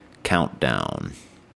描述：与科幻相关的口头文本样本。
Tag: 语音 英语 科幻小说 美国航空航天局 电火花 声乐 口语 空间